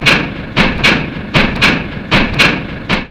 Clunk_noise_-_machinery.mp3